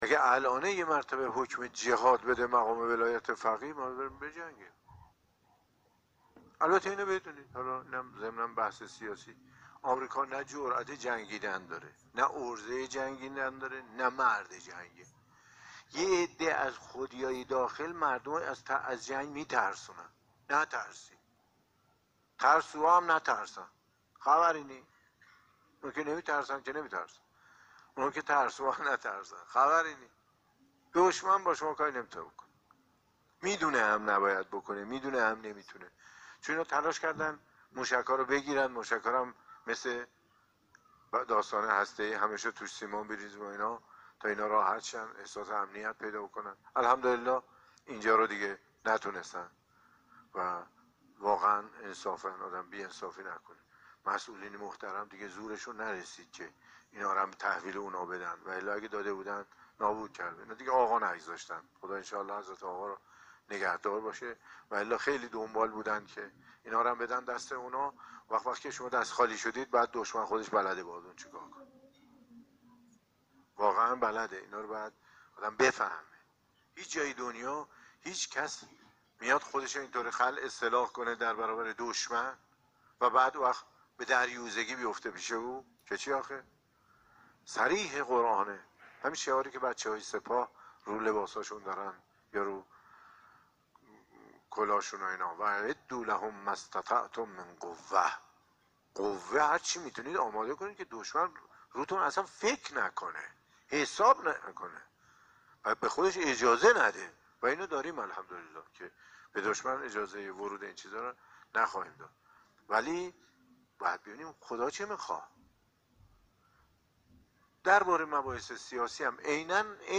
به گزارش خبرنگار خبرگزاری رسا، حجت الاسلام والمسلمین مرتضی آقاتهرانی استاد حوزه علمیه، شب گذشته در جلسه معرفتی ماه مبارک رمضان که در مسجد شهید بهشتی برگزار شد، گفت: همه دستورات الهی بر محور توحید استوار است.